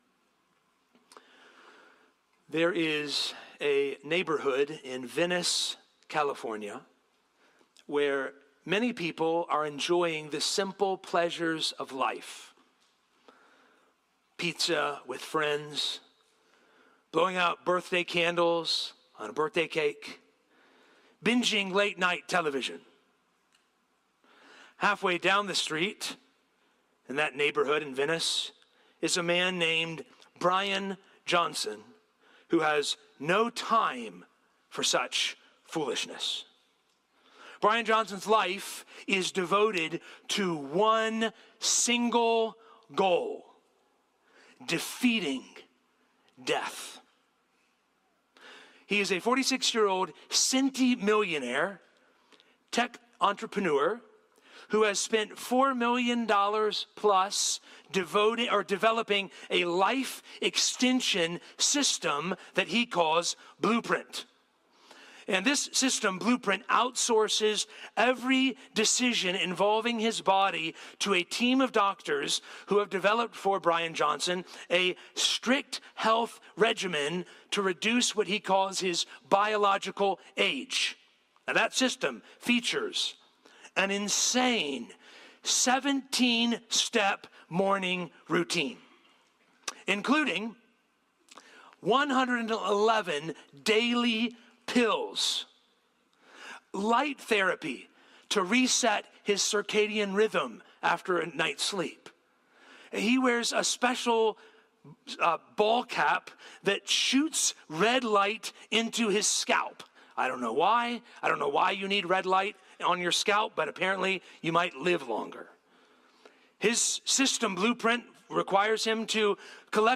Sermons | Poquoson Baptist Church